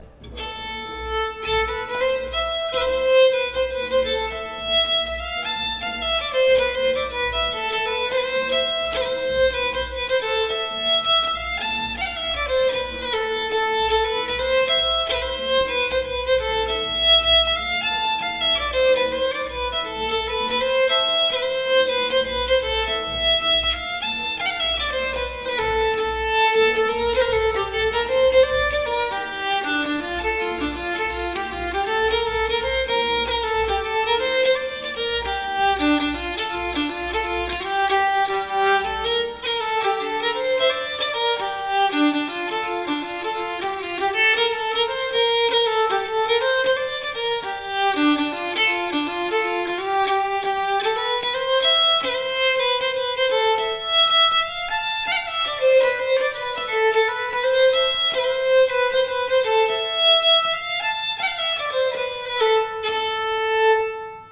Genre Schottis
Födelsedagsschottis.mp3